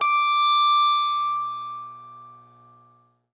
SPOOKY    BA.wav